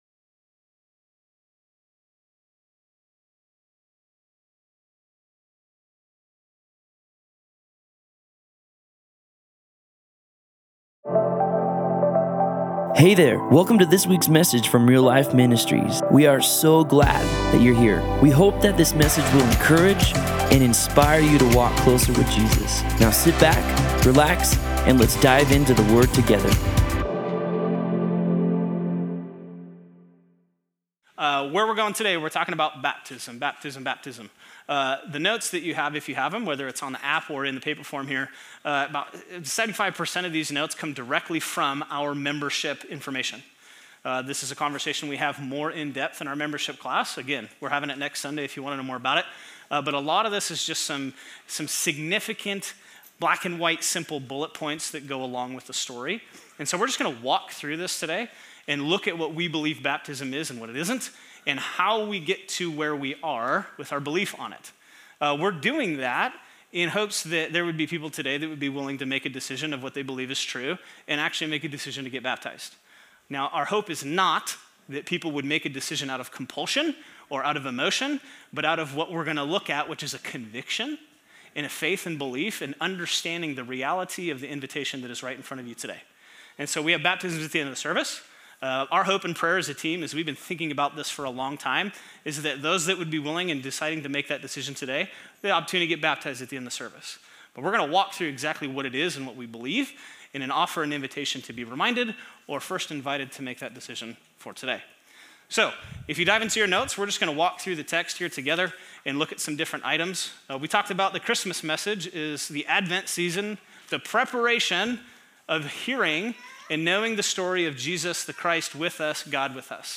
Baptism+Service+CDA+Campus+Audio.mp3